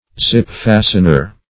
Search Result for " zip-fastener" : The Collaborative International Dictionary of English v.0.48: zip-fastener \zip"-fa`stener\, n. 1. a device for locking together two toothed edges by means of a sliding tab.